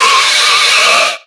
Cri de Scobolide dans Pokémon X et Y.